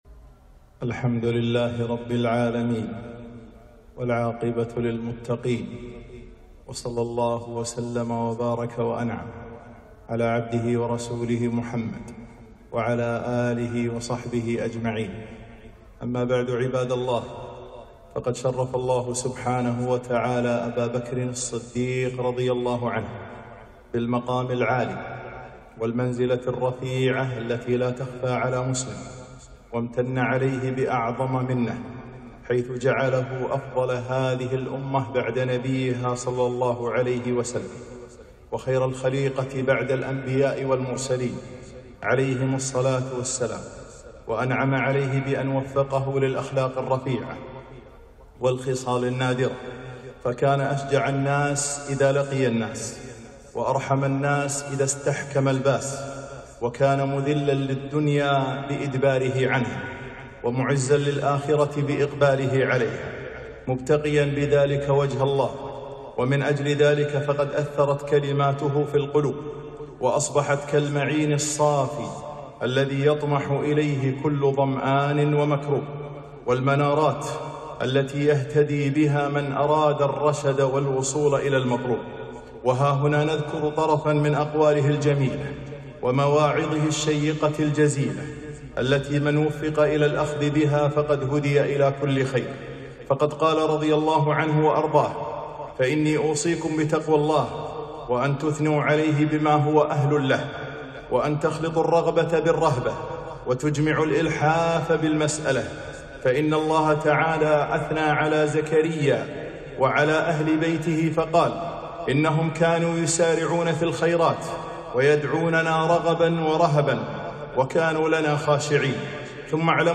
خطبة - من مواعظ أبي بكر الصديق رضي الله عنه